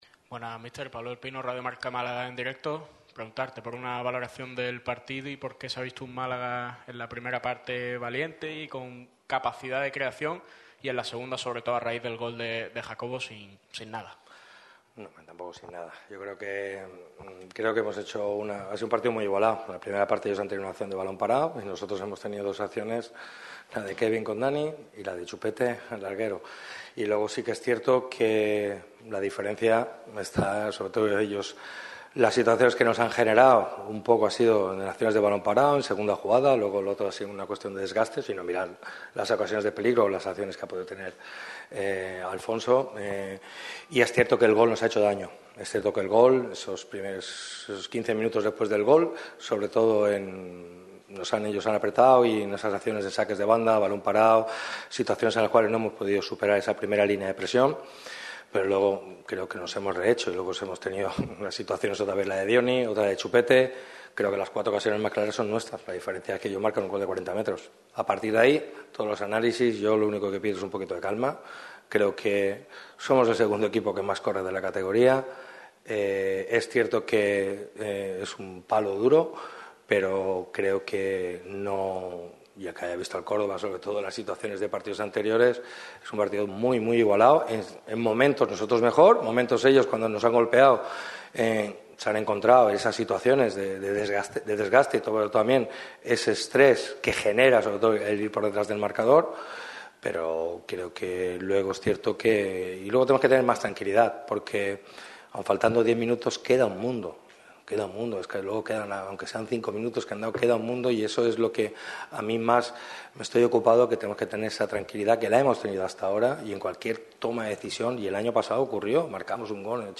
Tras la disputa del mismo, el entrenador del Málaga CF ha comparecido ante los medios para hablar sobre el encuentro y analiza las claves de la derrota boquerona en la tarde de hoy.